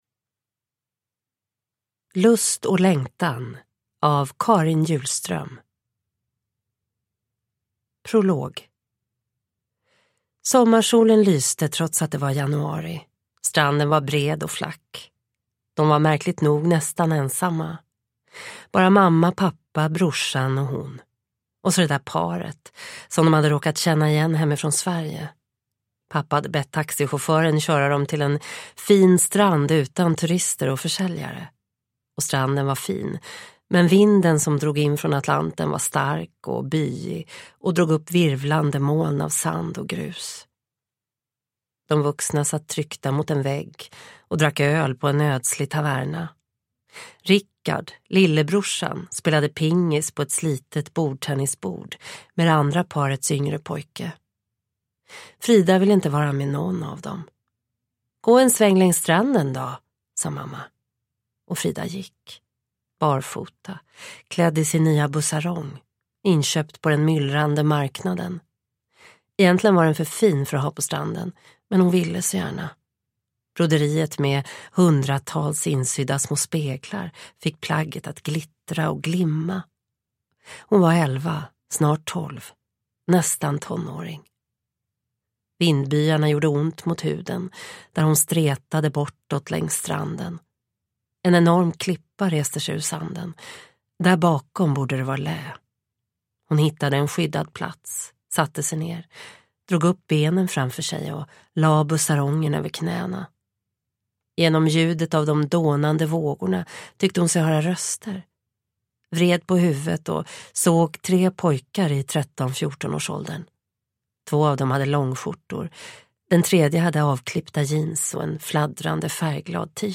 Ljudbok